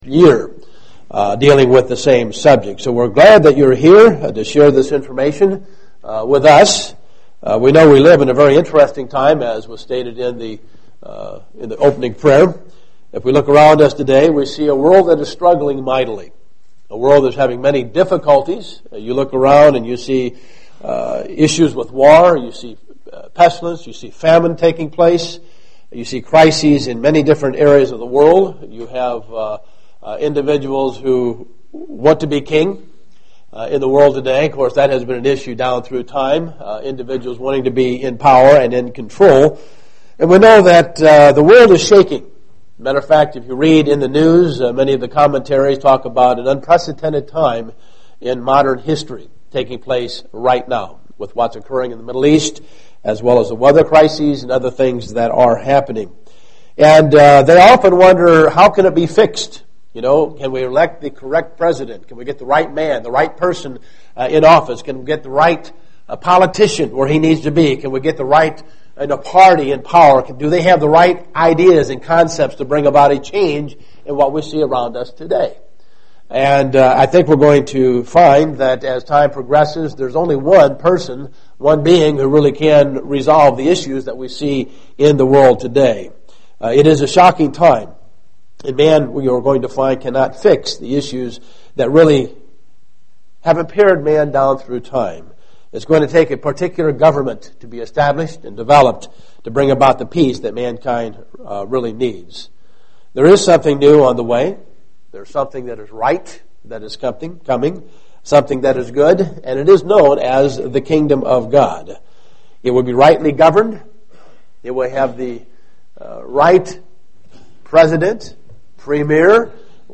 Learn more in this Kingdom of God seminar.
Given in Dayton, OH
UCG Sermon Studying the bible?